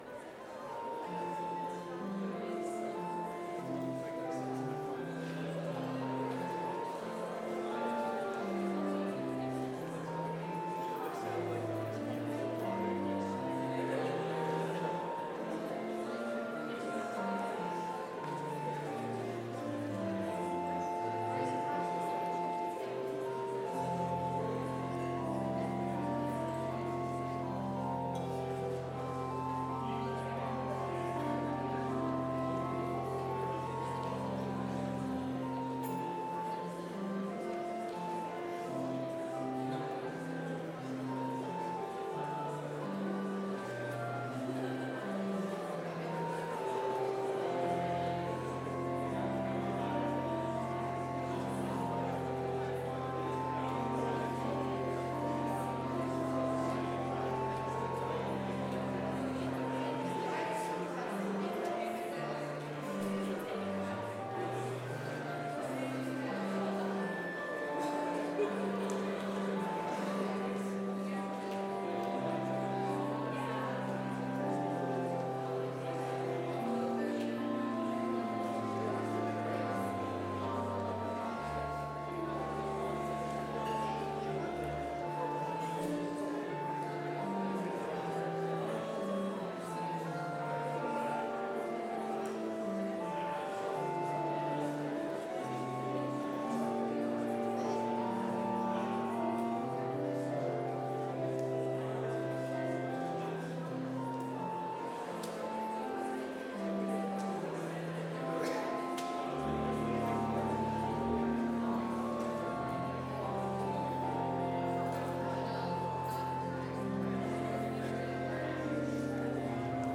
Complete service audio for Chapel - Wednesday, October 16, 2024